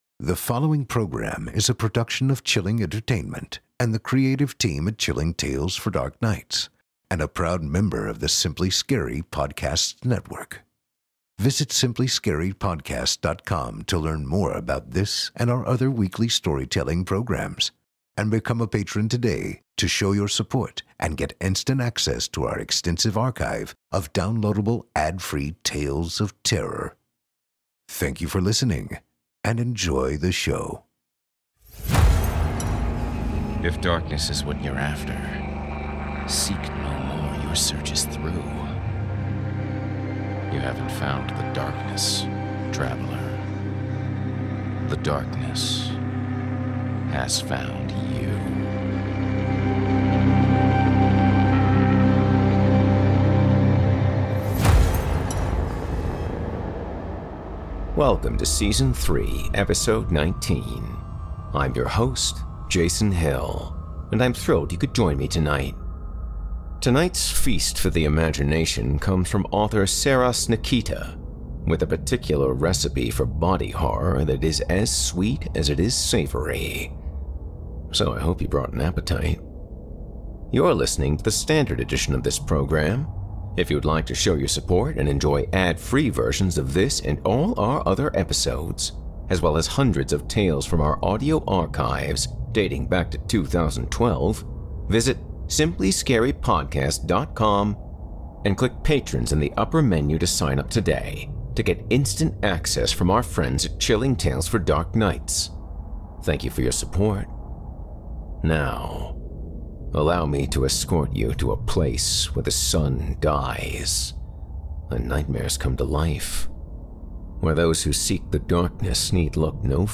performed by host and narrator